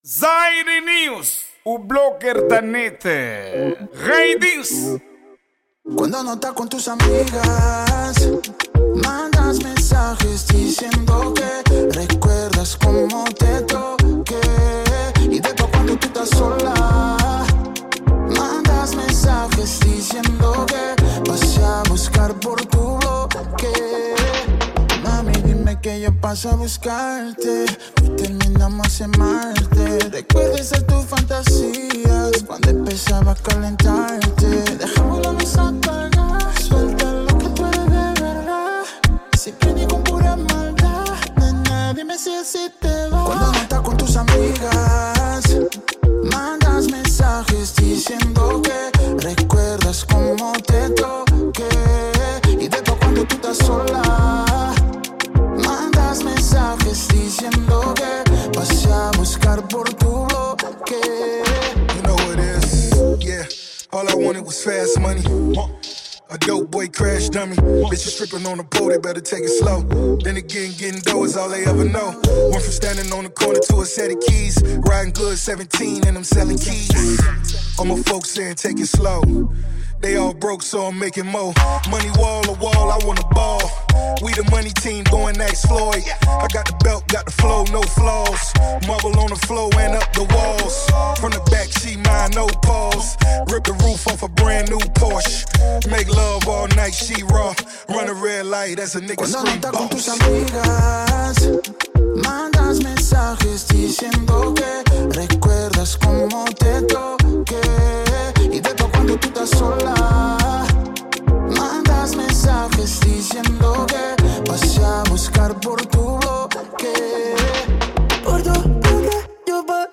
Afro Beat
Pop